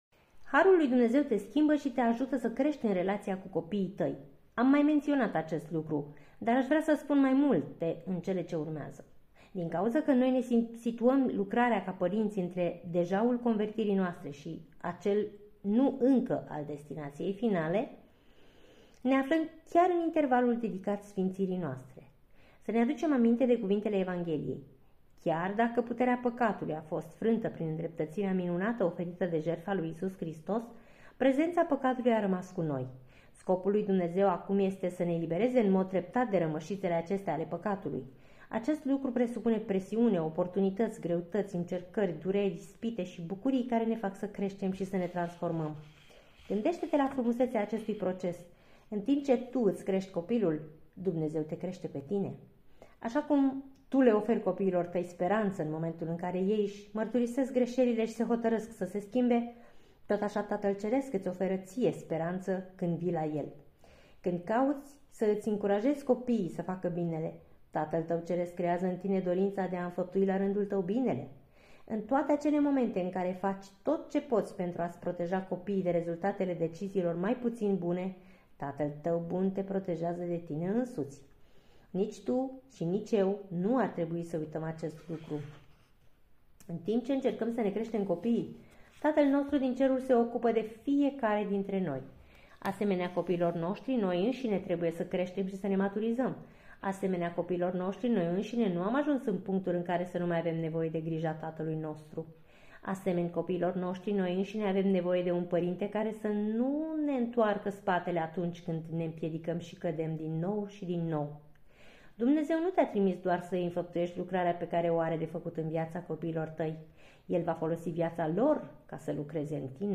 Este al doilea capitol al cărții "Pentru părinți - 14 principii care îți pot schimba radical familia" de la Paul David Tripp.